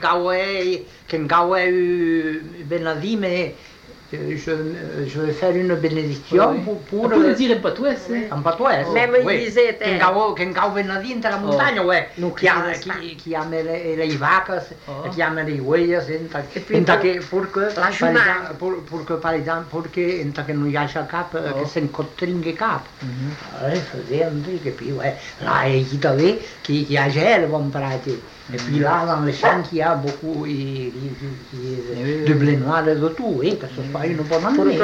Aire culturelle : Couserans
Genre : forme brève
Type de voix : voix d'homme
Production du son : récité
Classification : prière